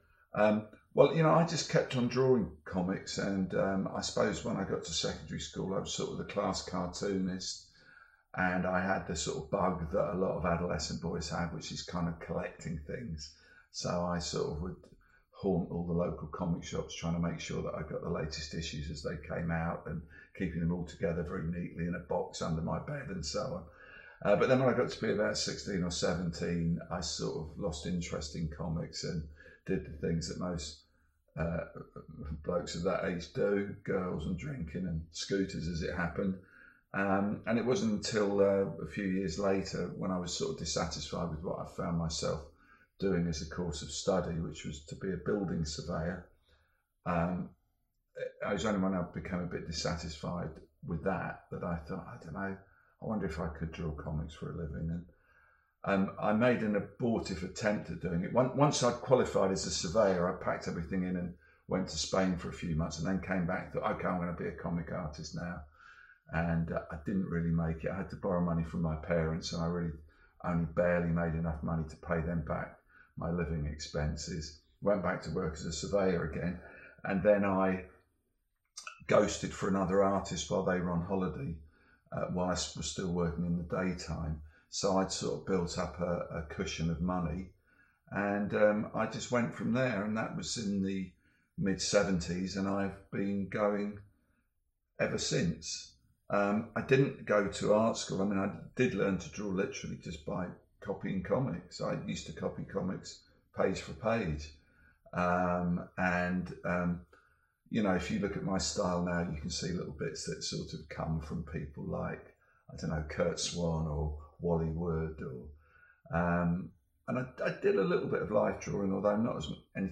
Dave Gibbons interview: From copying to comic book artist